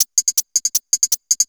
Metalico 01.wav